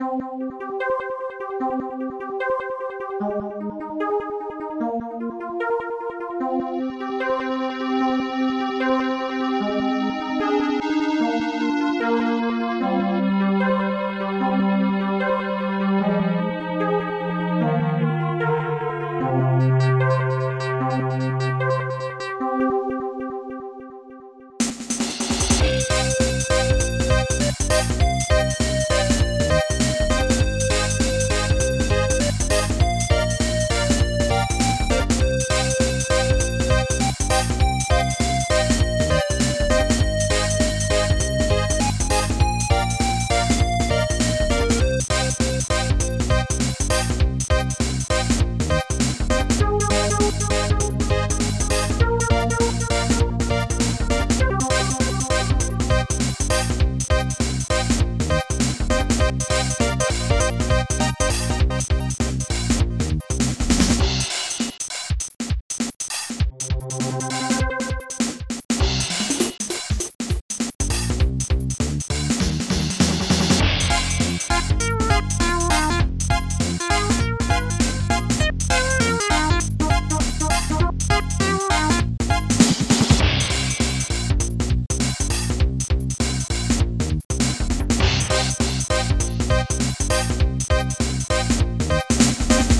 Protracker Module
tubestring bassdrum16 hihatop1 snare8 ffdunkbass reverb1 reverb2 hihat1 growl2 cymbal1 bolbus